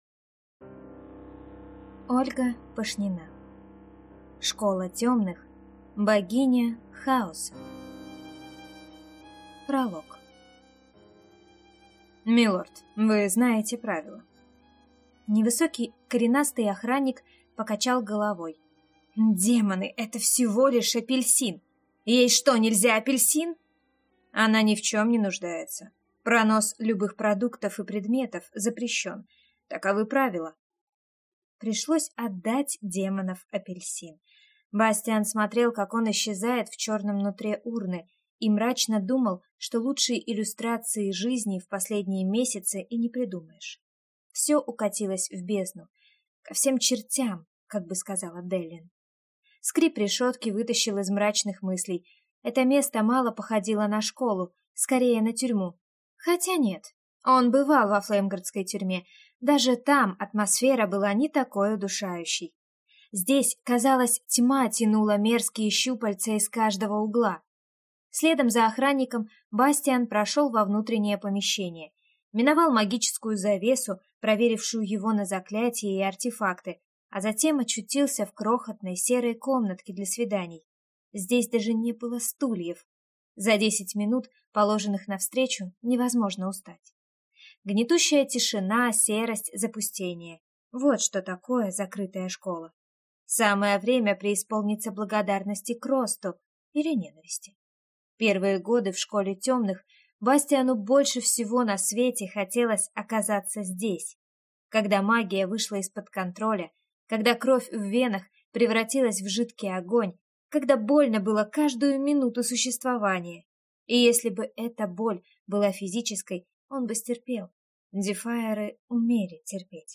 Аудиокнига Школа темных.